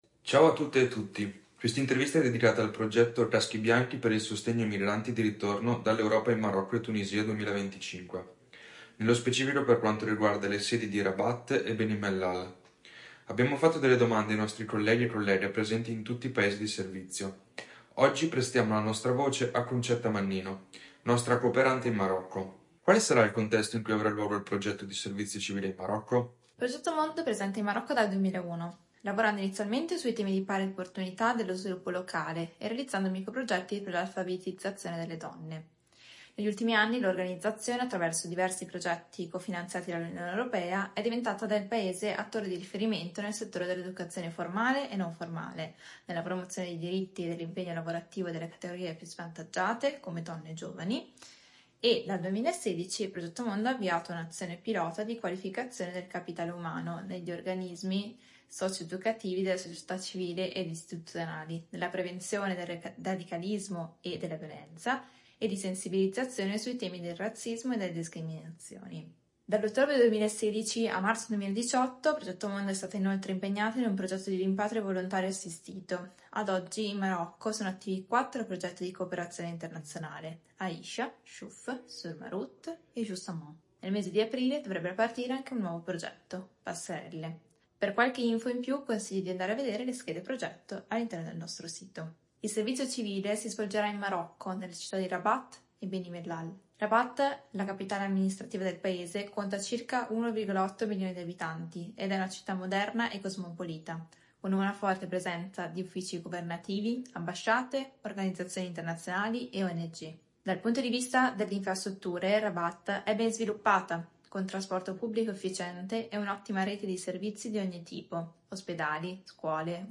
INTERVISTA 3